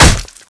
Index of /server/sound/weapons/tfa_cso/m249ep
hit1.wav